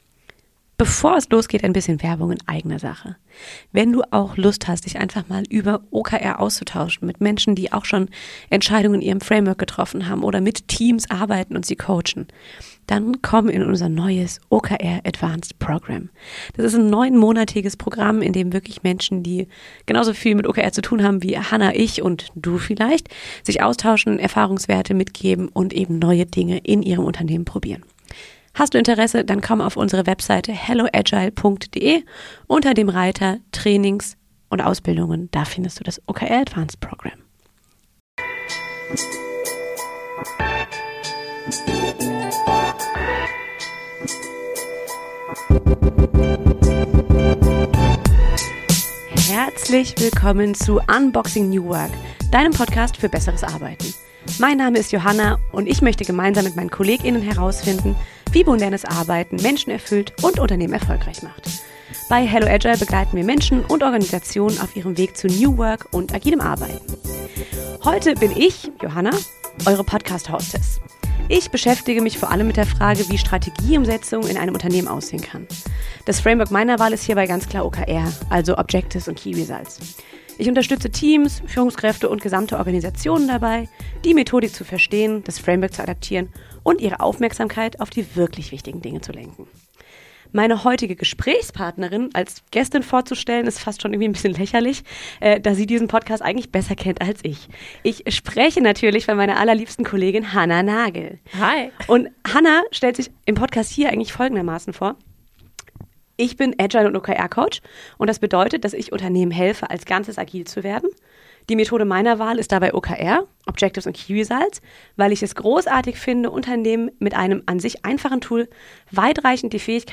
In dieser Folge diskutieren zwei OKR-Expertinnen die Fragen, die man nicht mal eben googeln kann: Braucht ein gutes Objective wirklich eine Zahl?